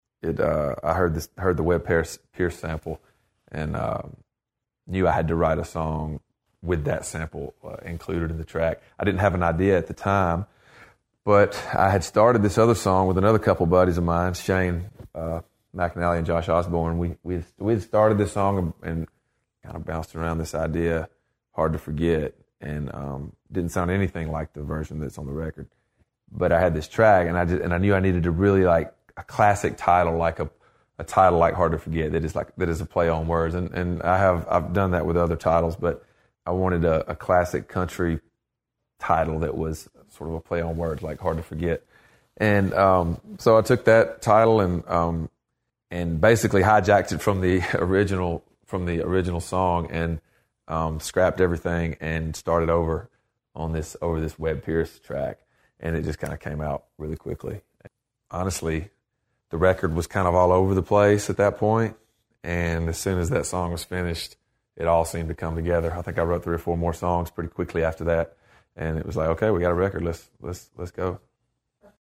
Audio / Sam Hunt says when they finished “Hard to Forget,” the new record just fell into place.